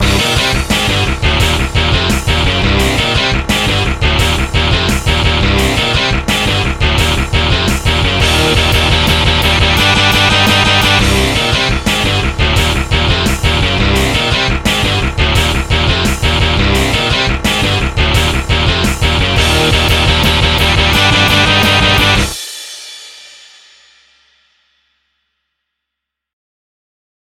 MIDI 7.64 KB MP3